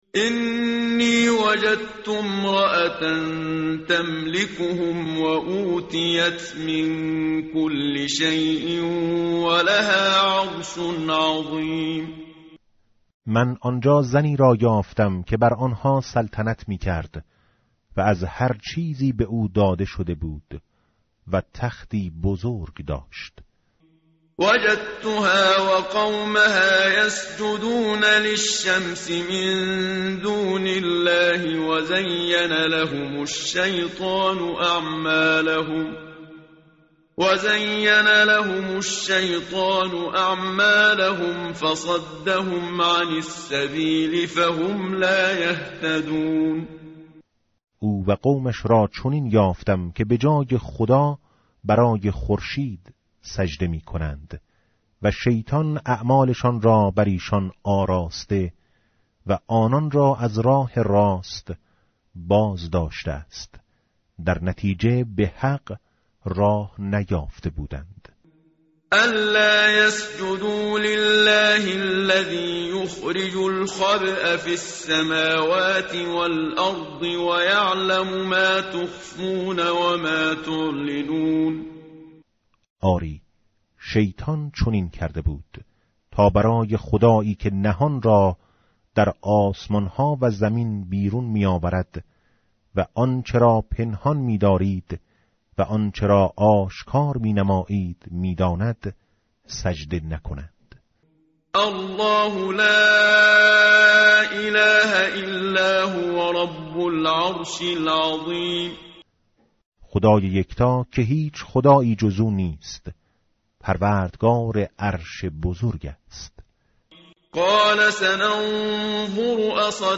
متن قرآن همراه باتلاوت قرآن و ترجمه
tartil_menshavi va tarjome_Page_379.mp3